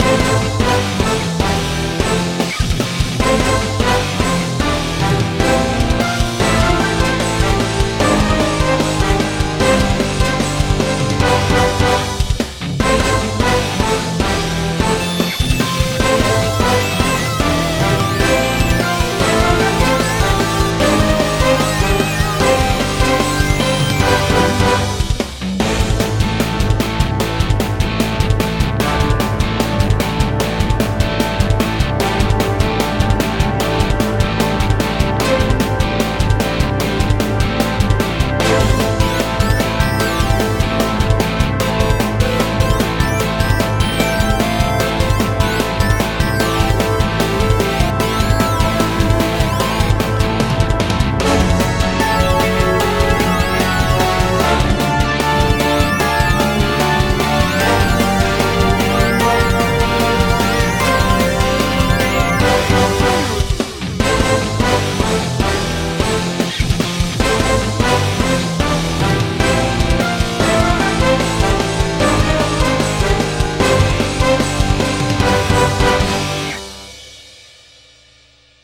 dog wrestling
dogwrestling.mp3